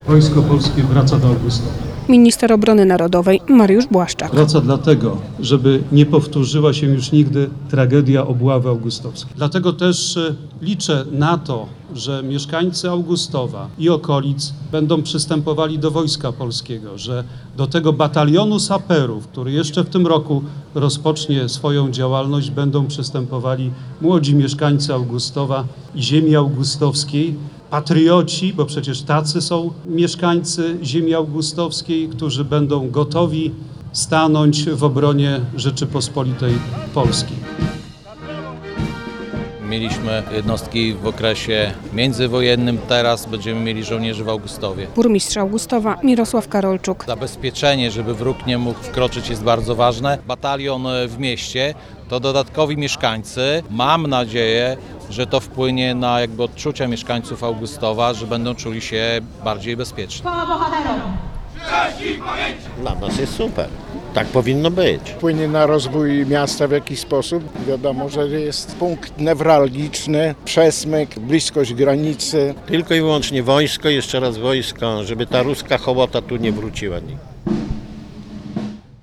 Taką informację ogłosił w niedzielę (23.07) na Rynku Zygmunta Augusta minister obrony narodowej Mariusz Błaszczak.
Jeszcze w tym roku w Augustowie powstanie batalion saperów - zapewnił Mariusz Błaszczak | Pobierz plik.